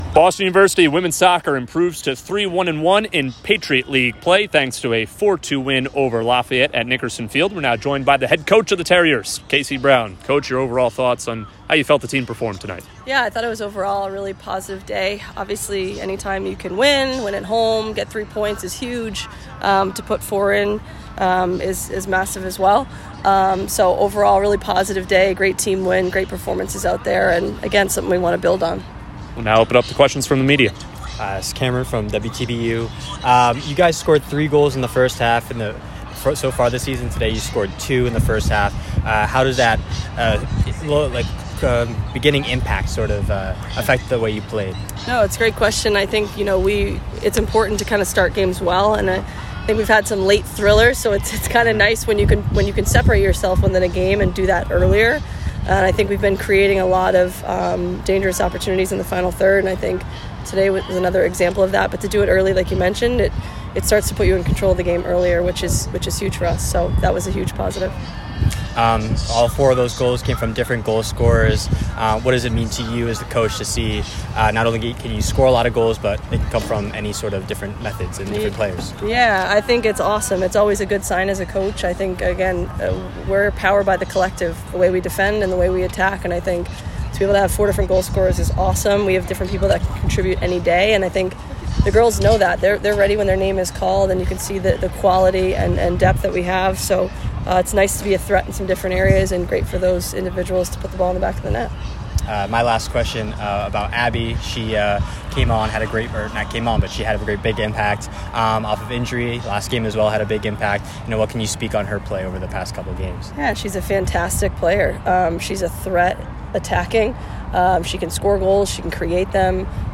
WSOC_Lafayette_Postgame.mp3